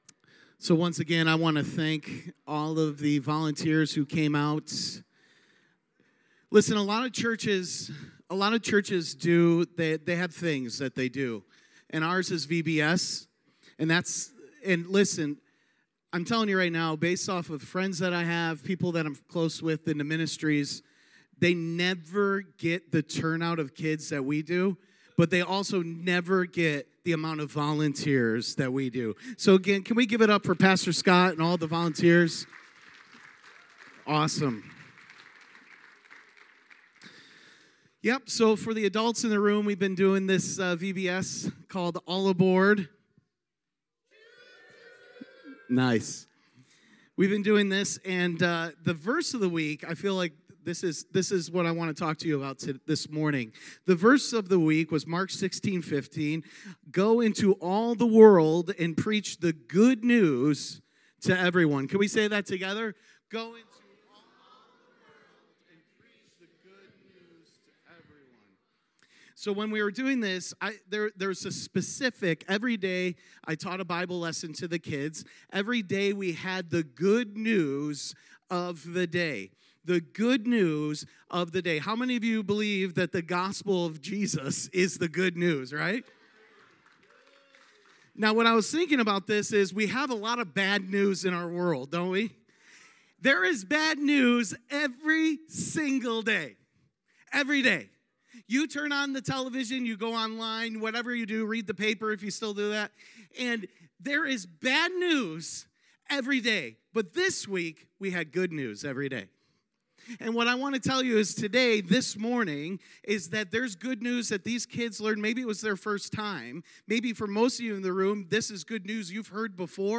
good news vbs recap sermon 7 24 22.mp3